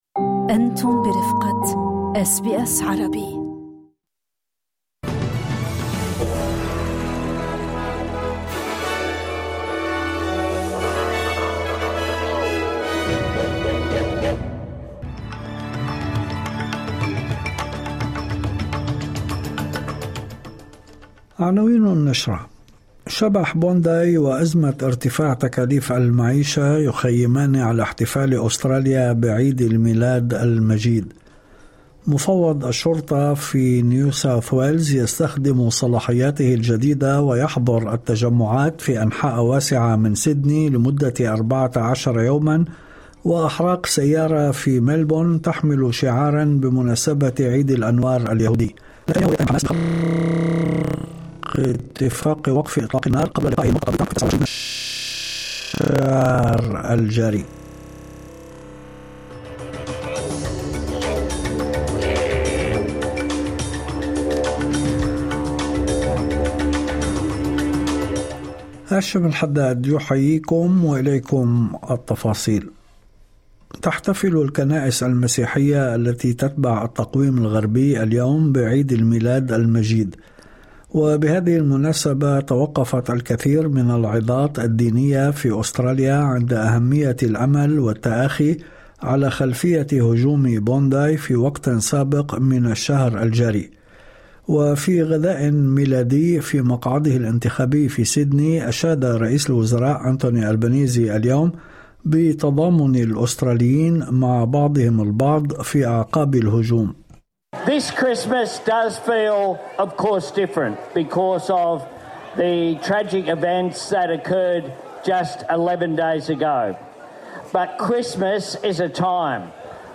نشرة أخبار المساء 25/12/2025